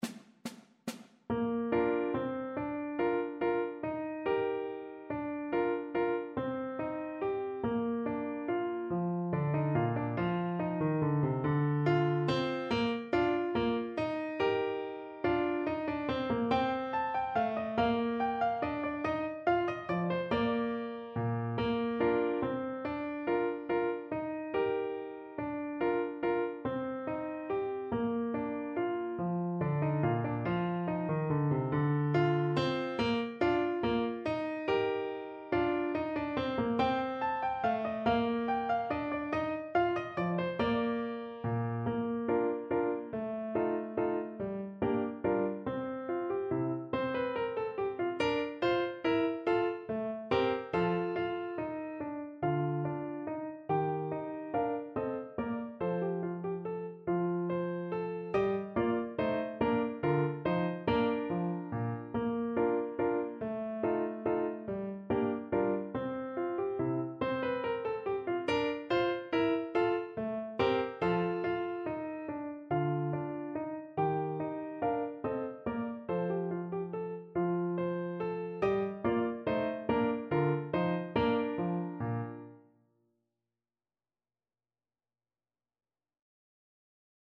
Bach: Menuet G-dur (klarnet i fortepian)
Symulacja akompaniamentu
Bach_Menuet-G_nuty_cl_pf-accompaniment.mp3